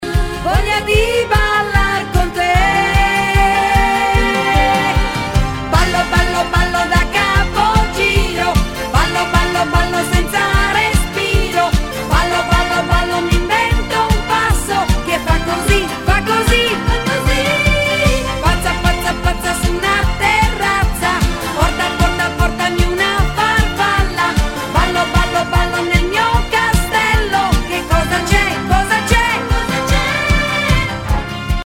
Mi pare evidente la differenza di qualità!